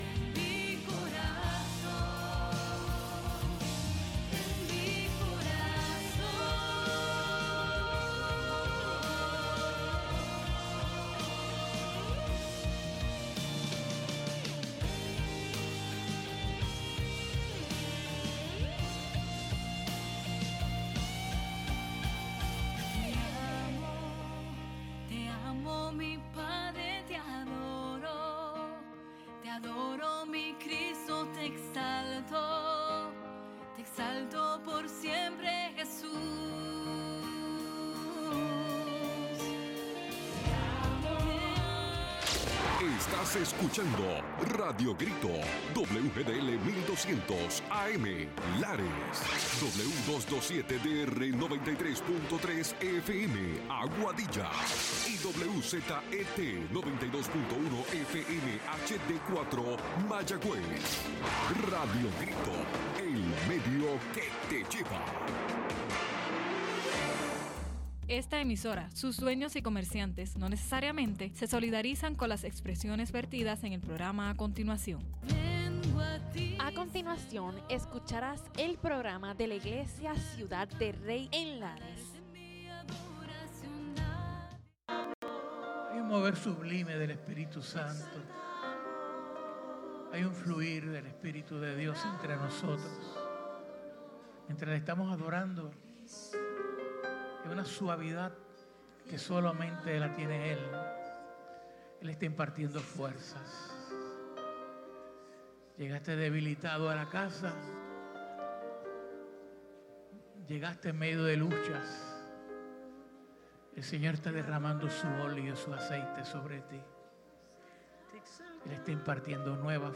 Los hermanos de Ciudad del Rey nos traen un programa especial desde el servicio de su iglesia.